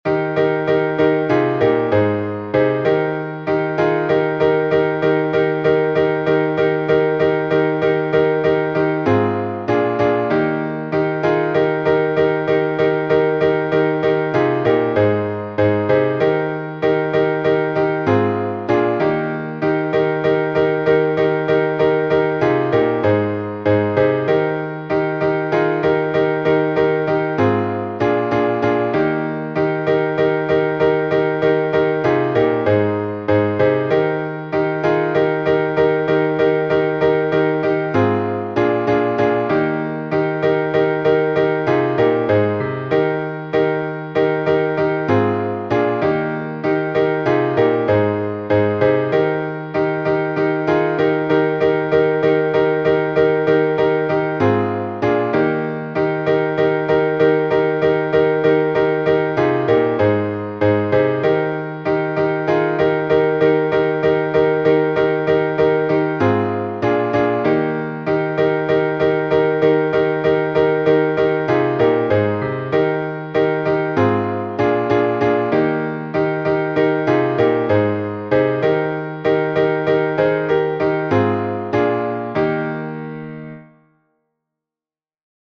Знаменный распев